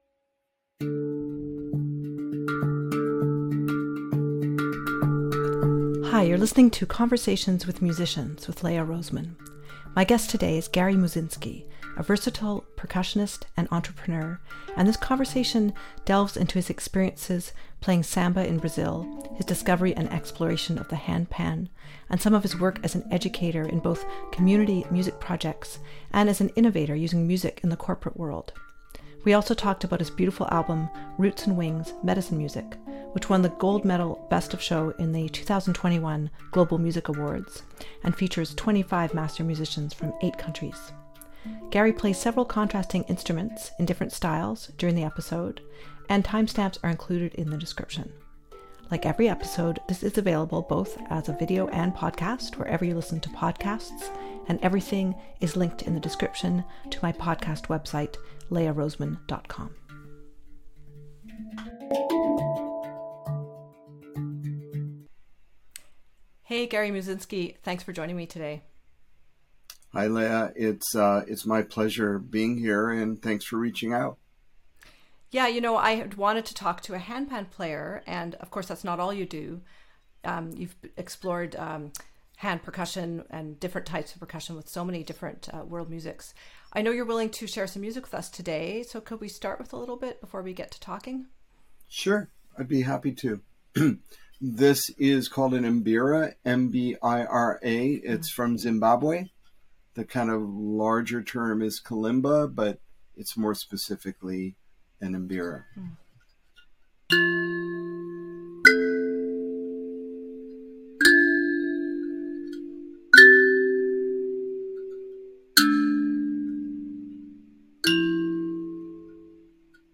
(01:31) Mbira music
(38:44) demonstration of some samba rhythms on agogô and surdo
(01:27:29) handpan improvisation